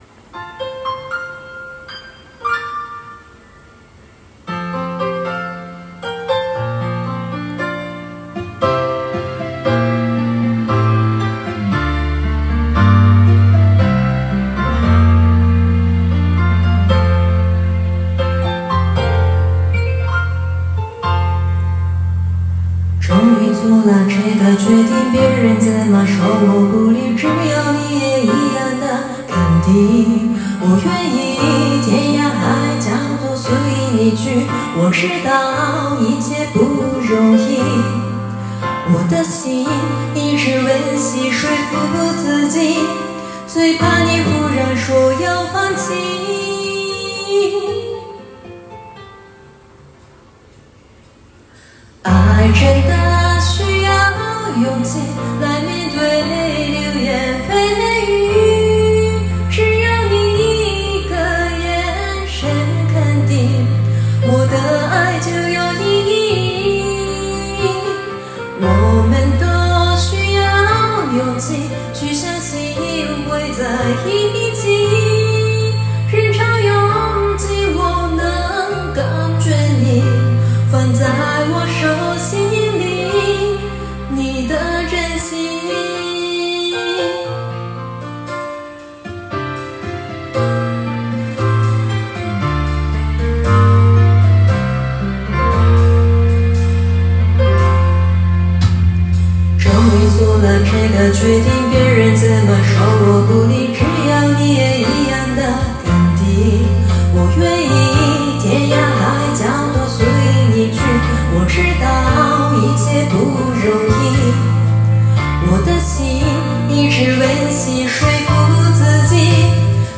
歌唱コンテスト結果発表
・发音很清晰，声音也很动听，歌声和伴奏完美结合
・发音清晰；感情充沛；节奏感好；声音很有吸引力，我超级喜欢
・节奏精准、情感充沛、很完美地处理了高音破音的尴尬。
何回かカラオケに1人で行って、録音しては、録音聴いてボツ、録音してはまたボツ…というのをひたすら繰り返して提出した作品ですw 下手の横好き…がぴったりですw